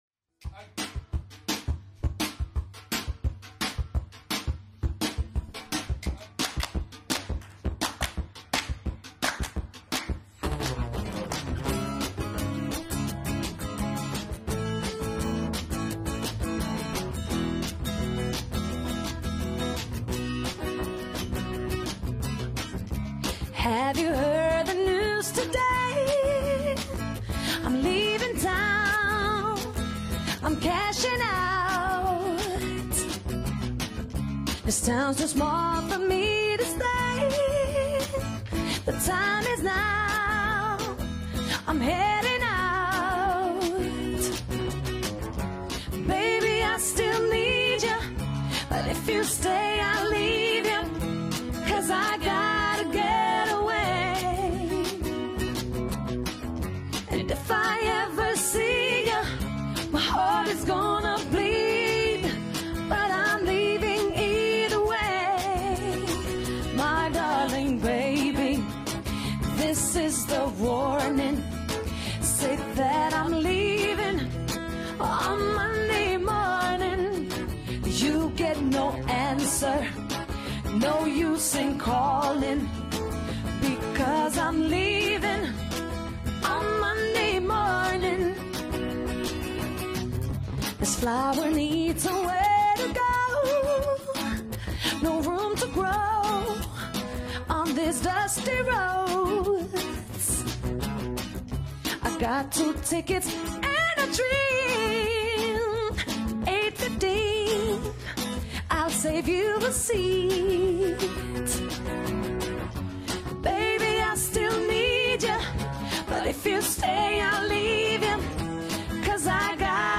Pjesme su u LIVE izvedbi, u realnim atmosferama.